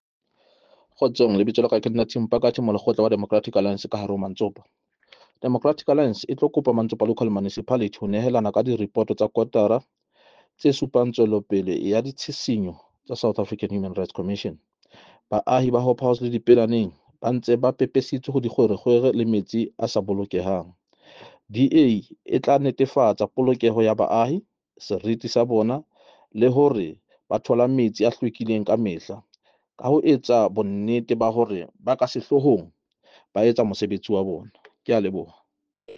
Sesotho soundbites by Cllr Tim Mpakathe.